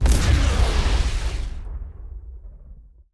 hit_spider.wav